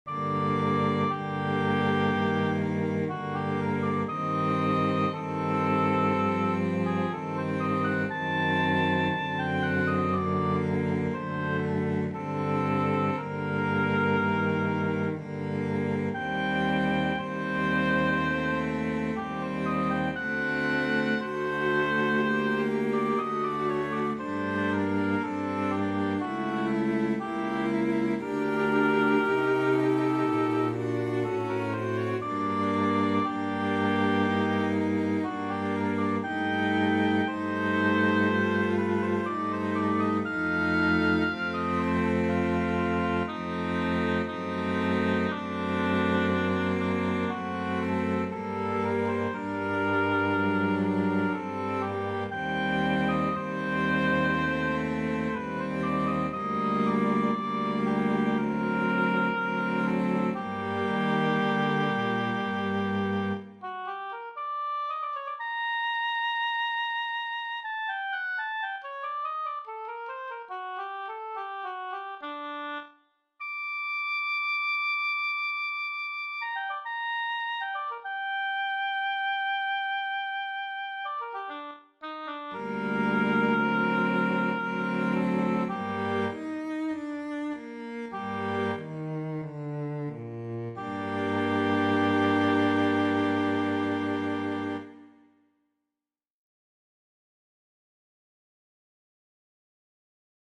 The first movement of a Quartettino for oboe and strings, attributed to Swabia’s
by the renowned MacFinale Ensemble playing period midi instruments!
Adagio